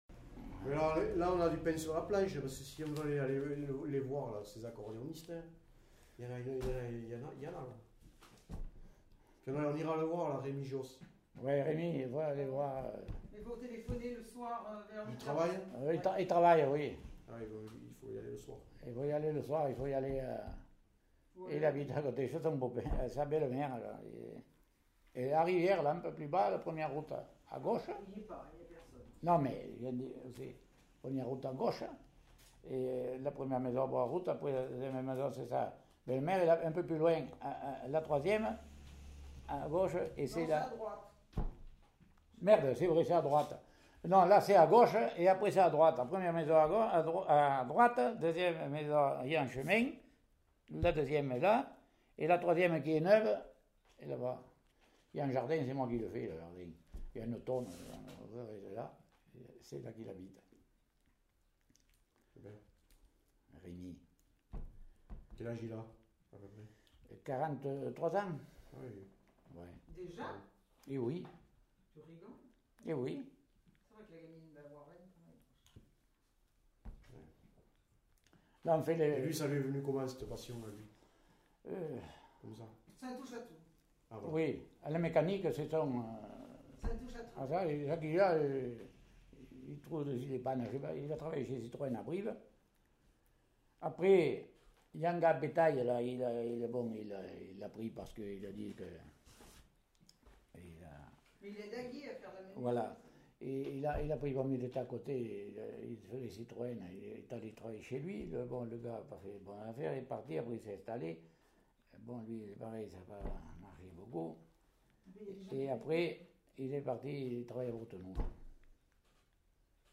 Aire culturelle : Quercy
Lieu : Vayrac
Genre : témoignage thématique